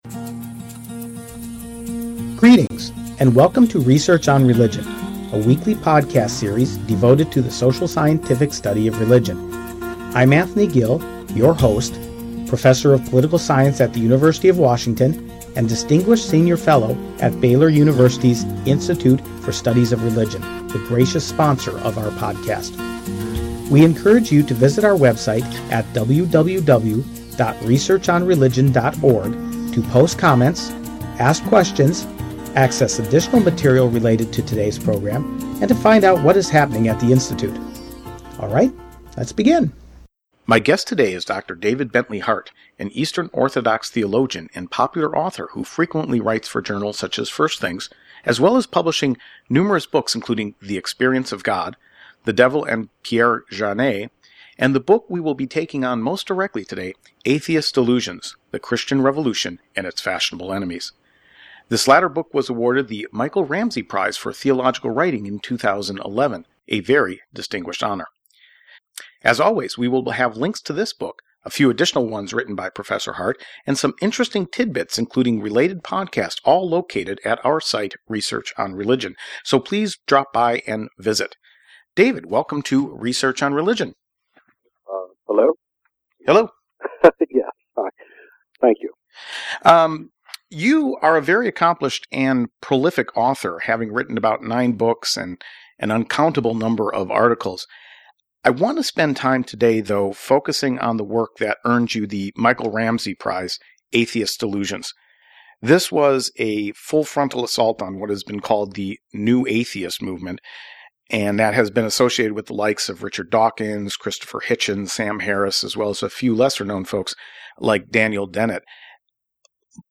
Dr. Hart, an Eastern Orthodox theologian and prolific writer, joins us to talk about how the book came about as well as his counter-critiques to the critiques of Christianity that are often made by the “new atheists” (as well as some of the older atheists) with a wee bit of churlishness here and there.